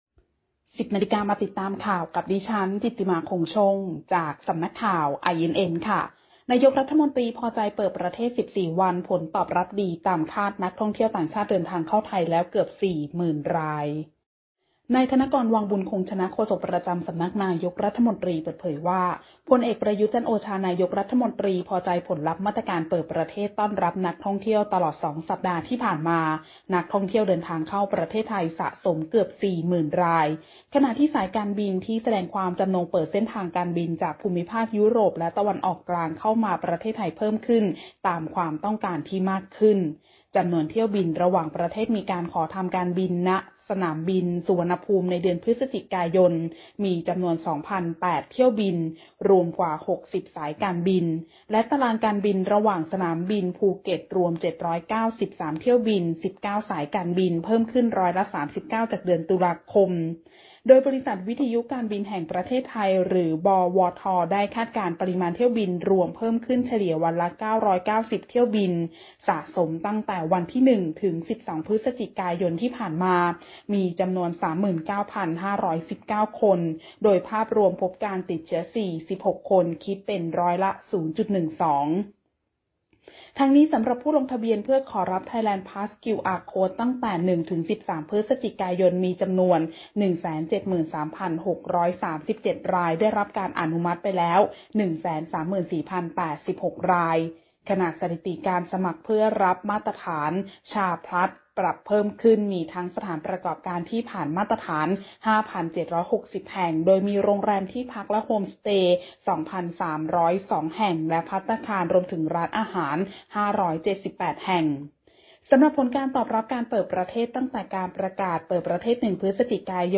ข่าวต้นชั่วโมง 10.00 น.